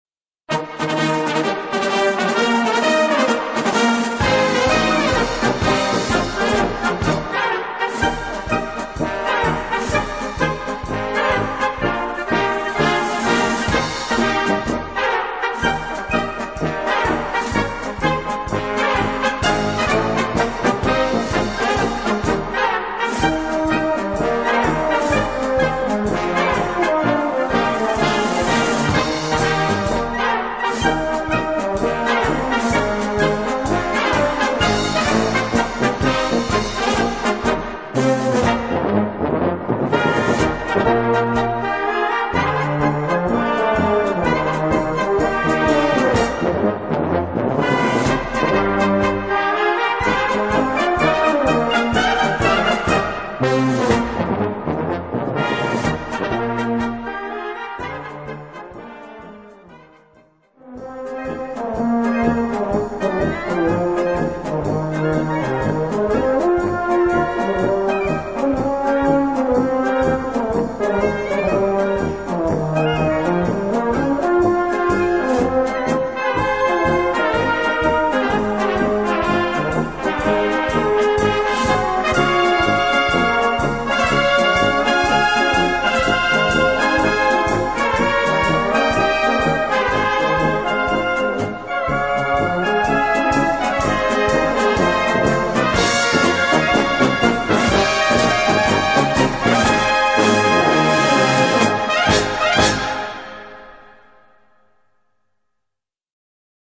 Gattung: Bravourmarsch
Besetzung: Blasorchester
Ein spritziger Konzertmarsch.